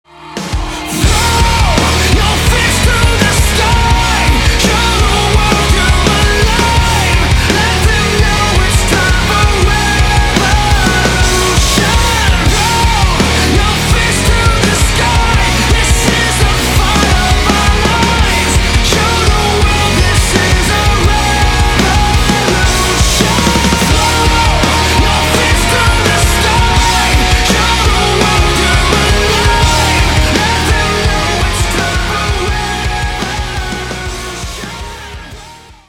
• Качество: 192, Stereo
громкие
Alternative Rock
энергичные
динамичные
post-grunge
Rock